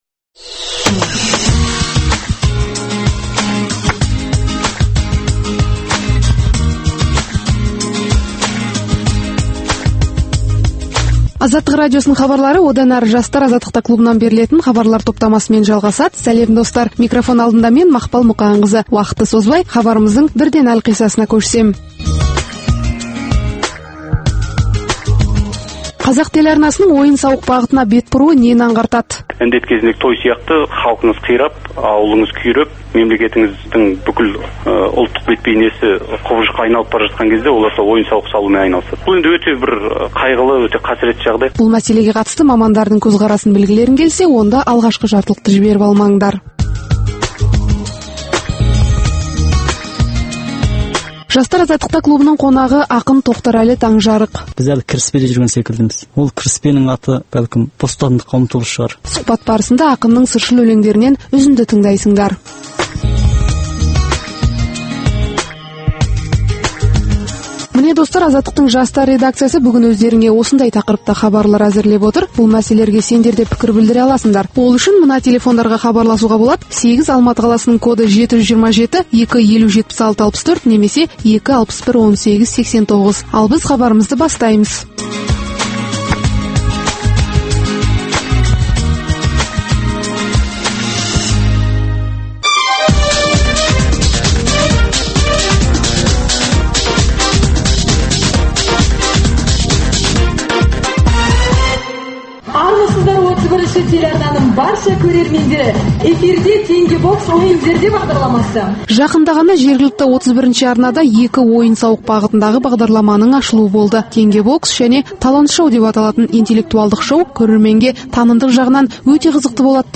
Пікірталас клубы